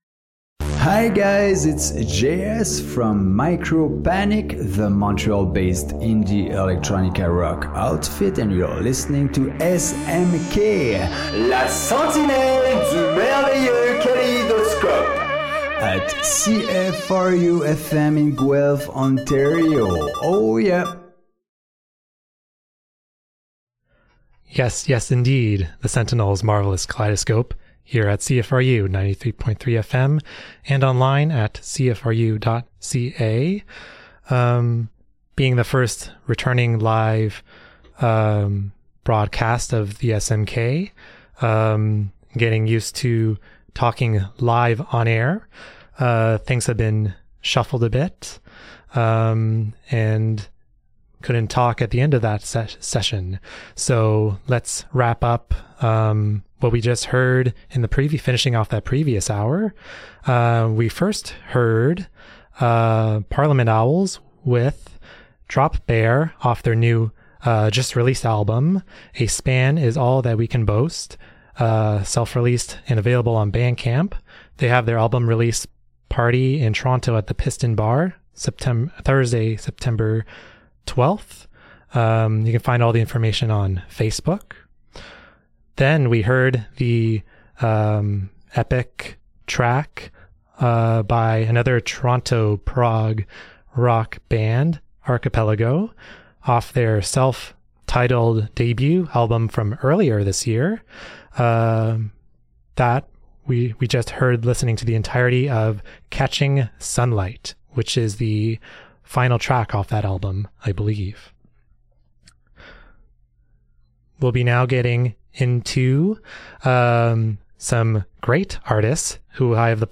Looking inward and beyond at progressive, fusion and alternative genres, near and far...